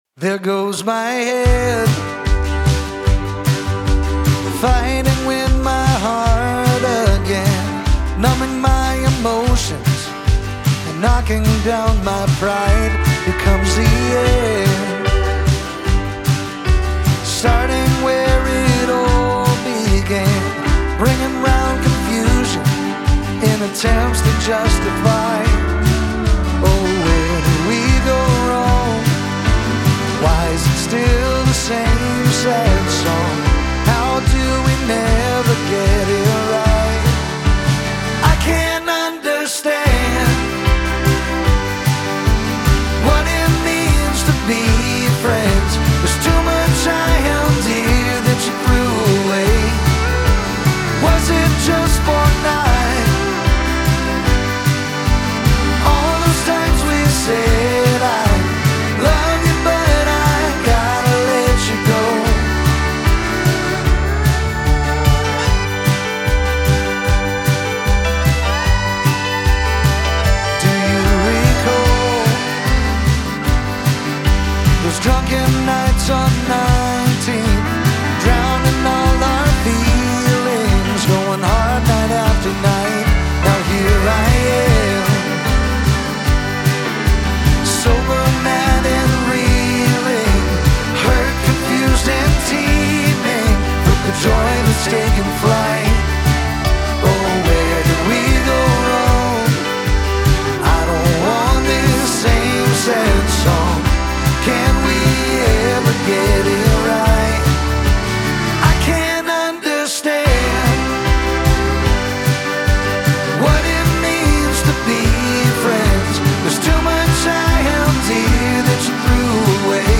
Steel Guitar